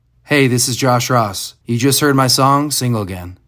LINER Josh Ross (Single Again) 5